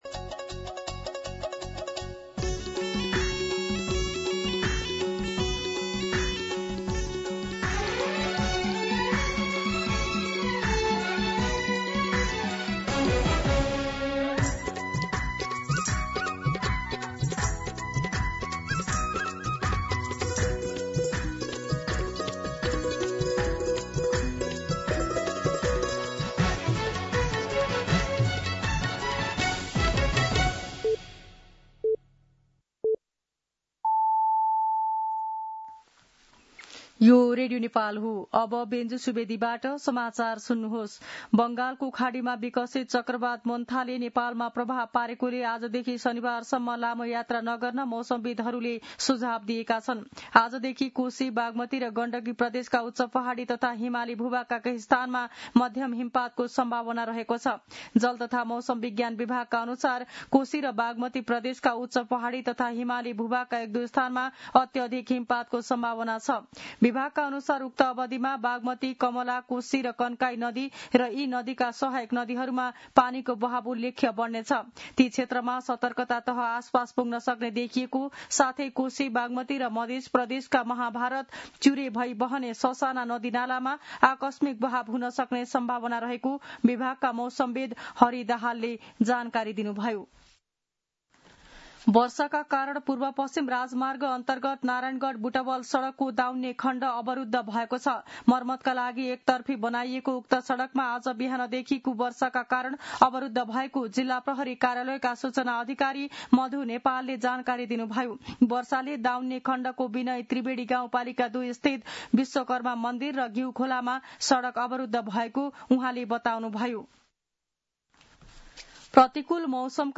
दिउँसो १ बजेको नेपाली समाचार : १३ कार्तिक , २०८२
1-pm-nepali-News.mp3